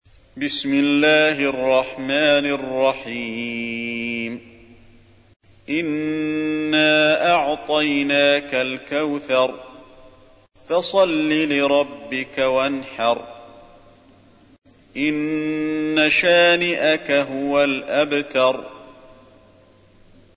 تحميل : 108. سورة الكوثر / القارئ علي الحذيفي / القرآن الكريم / موقع يا حسين